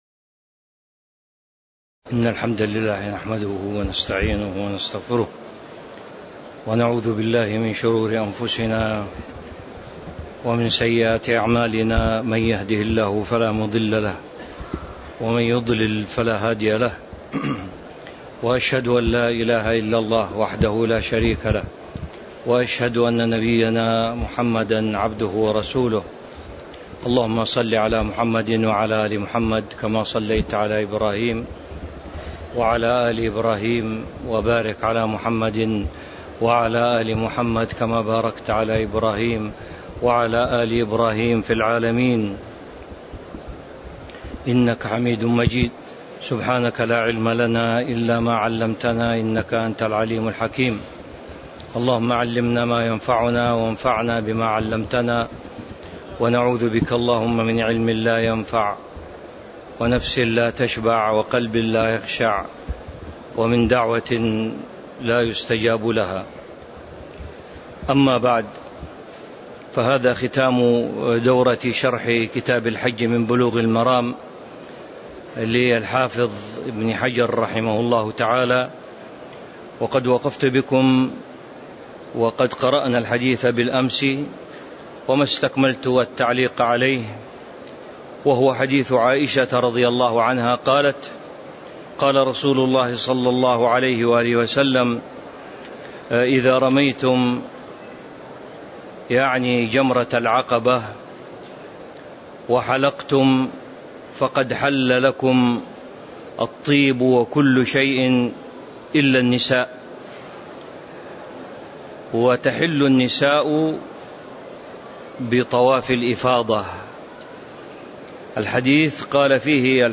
تاريخ النشر ٣ ذو الحجة ١٤٤٣ هـ المكان: المسجد النبوي الشيخ